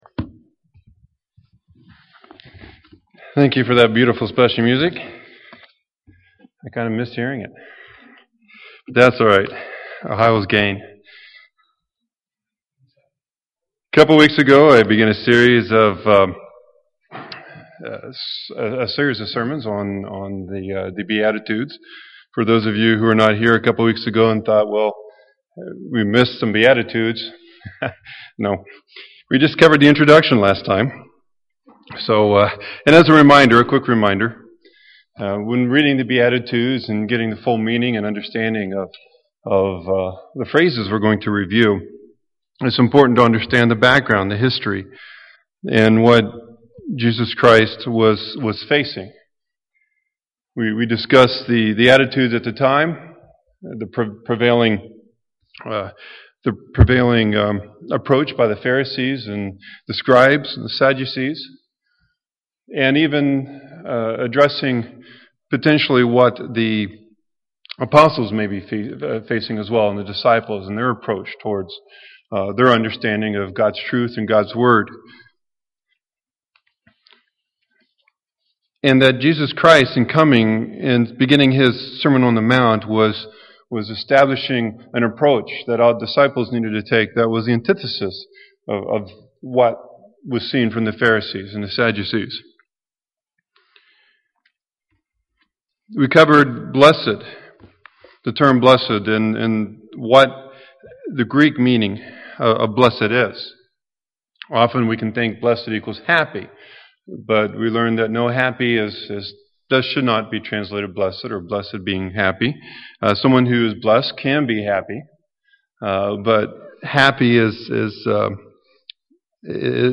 Given in Milwaukee, WI
Print A continuation of the study of the beatitudes UCG Sermon Studying the bible?